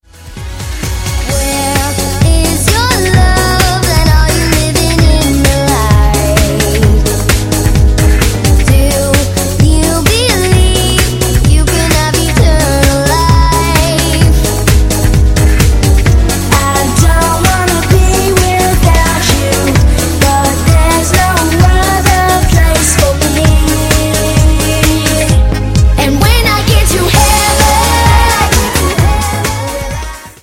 Performance, aktuellen Charts-Pop;
• Sachgebiet: Pop